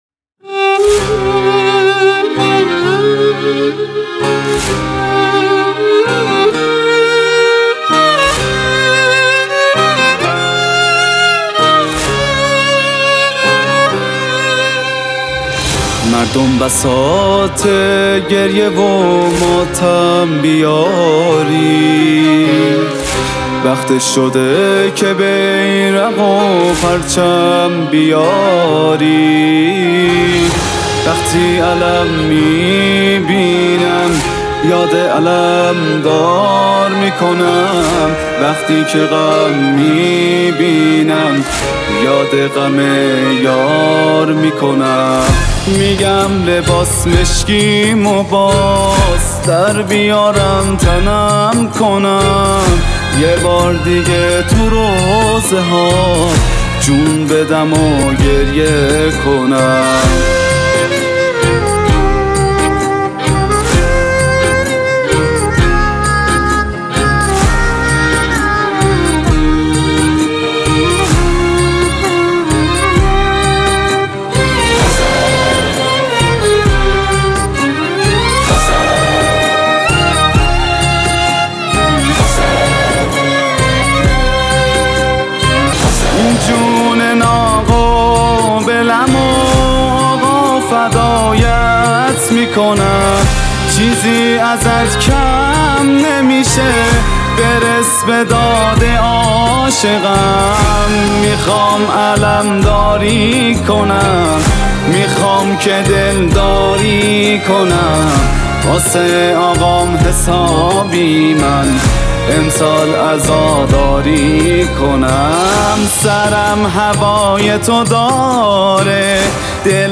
نوحه و مداحی